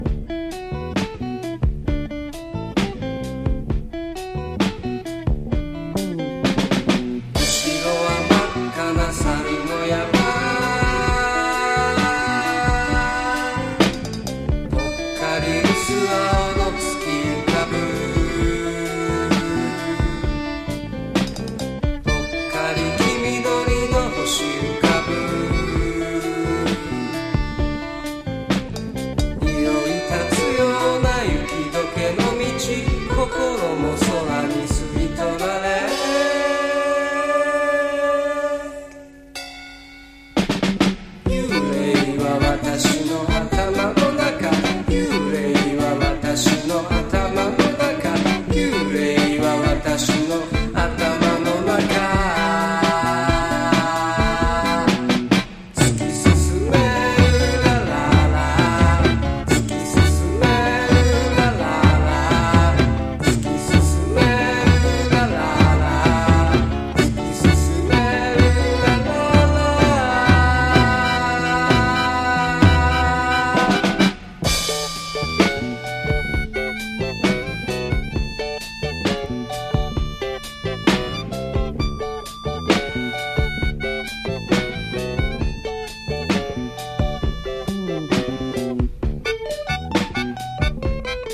NO WAVE / POST PUNK、東京ロッカーズ、あぶらだこ、ゆらゆら帝国好きまで必聴！